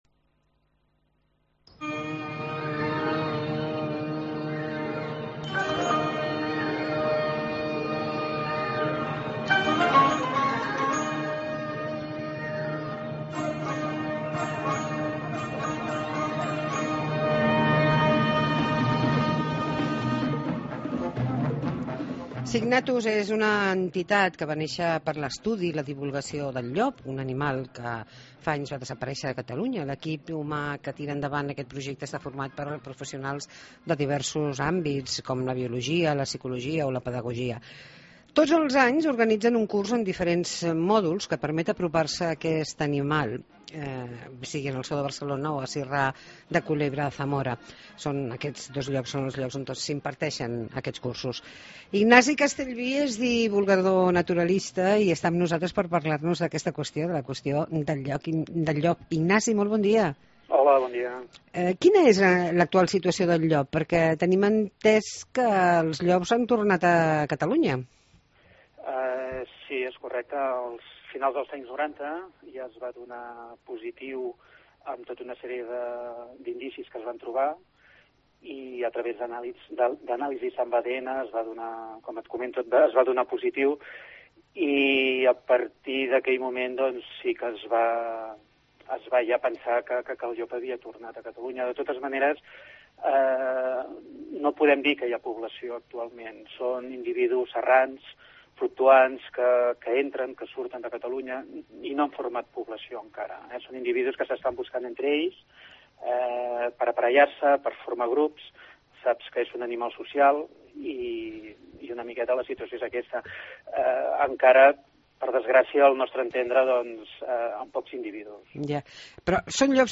Entrevista sobre el retorn del llop a Catalunya